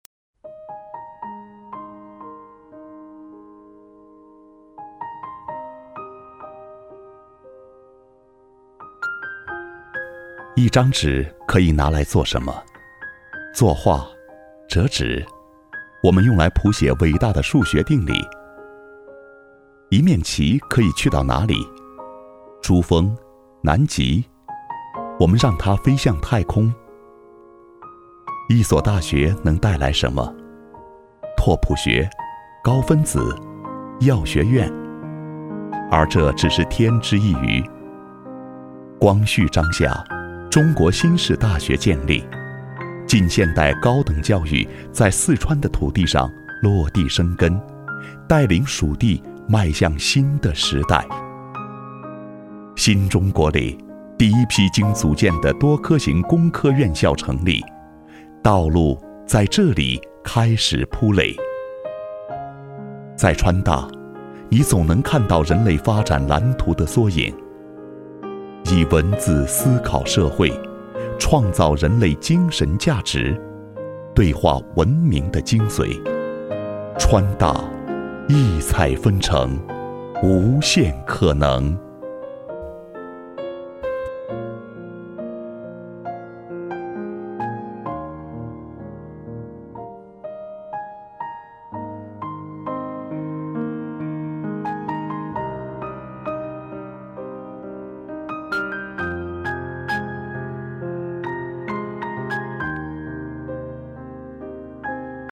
22 男国101_专题_学校_四川大学_温情 男国101
男国101_专题_学校_四川大学_温情.mp3